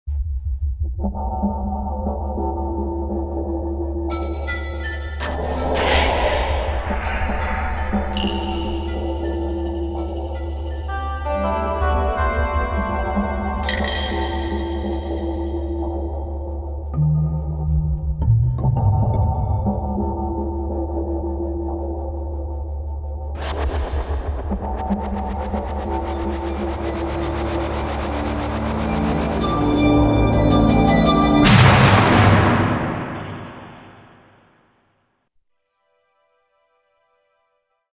MSC-14S; MPC-14S Sound Effects